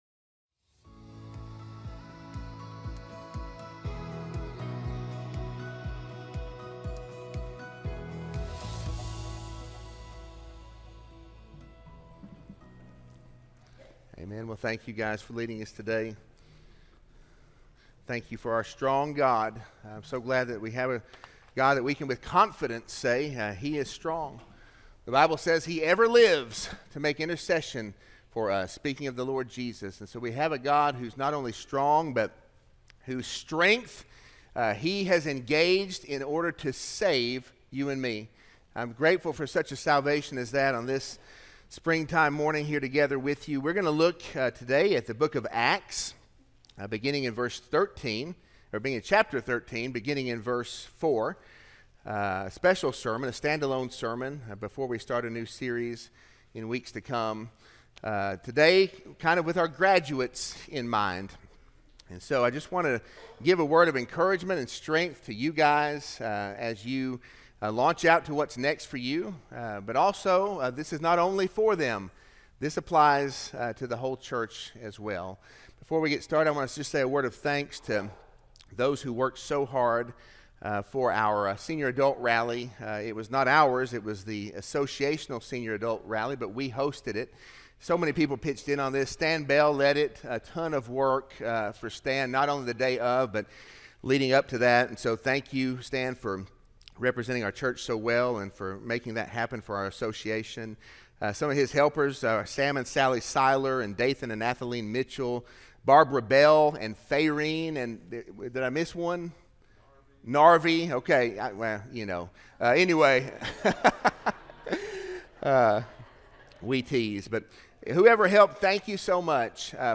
Sermon-5-4-25-audio-from-video.mp3